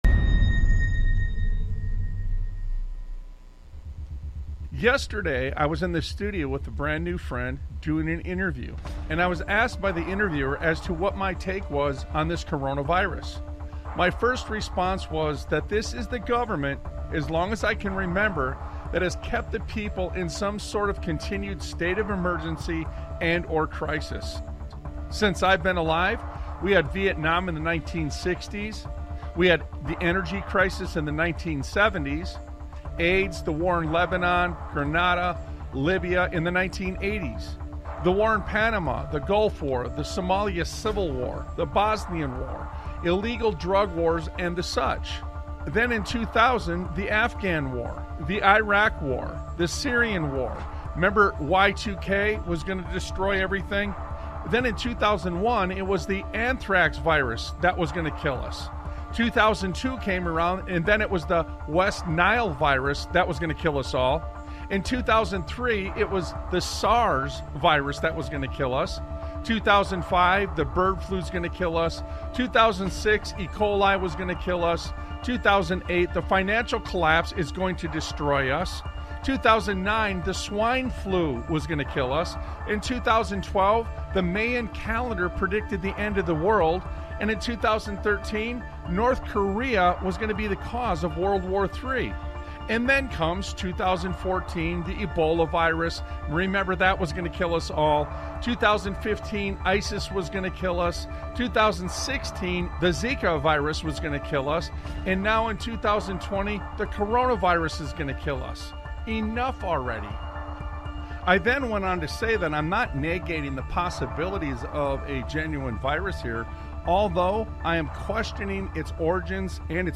Talk Show Episode, Audio Podcast, Sons of Liberty Radio and Out Of The Chaos That They Create on , show guests , about Out Of The Chaos That They Create,The Architecture of Manufactured Crisis: Liberty,Faith,and Resistance, categorized as Education,History,Military,News,Politics & Government,Religion,Christianity,Society and Culture,Theory & Conspiracy